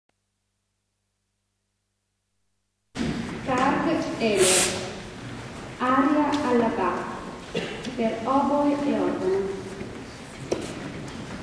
Concerto dell'Epifania
Chiesa di San Giorgio